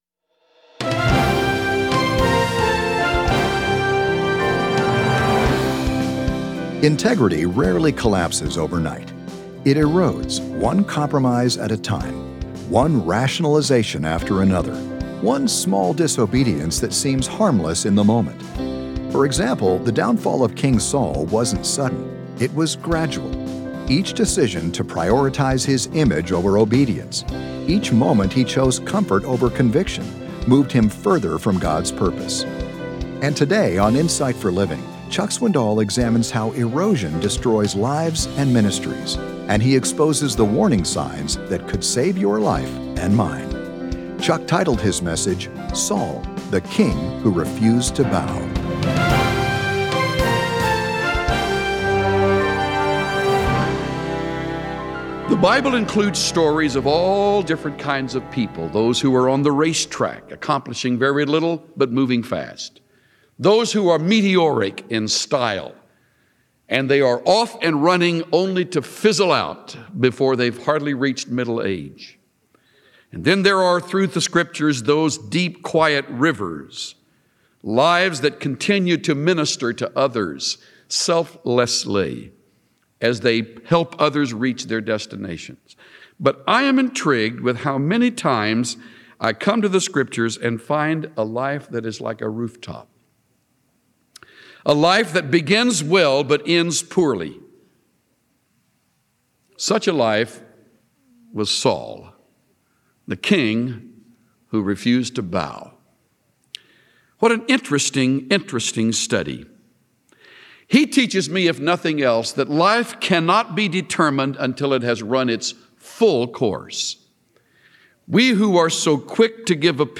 Tune in to hear Pastor Chuck Swindoll teach about a leader who took others too seriously while not taking God seriously enough. Find four reminders for all who walk with God.